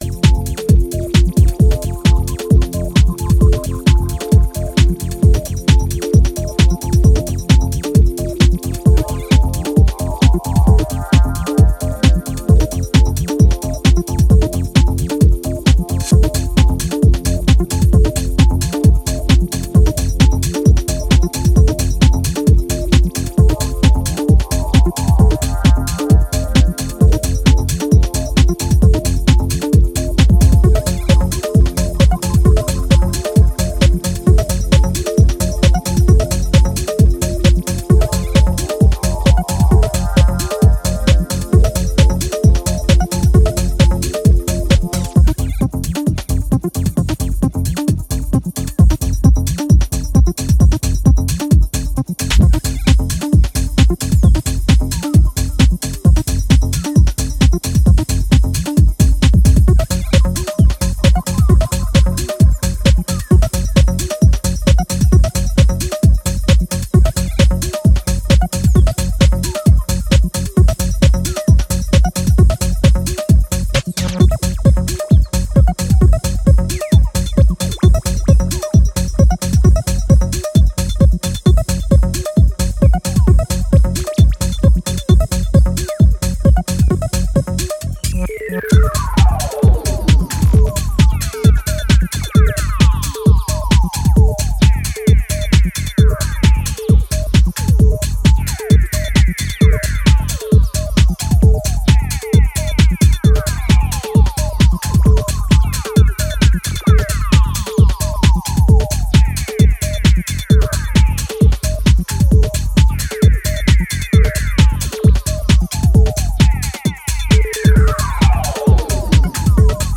ultra-slick and otherworldly club bombs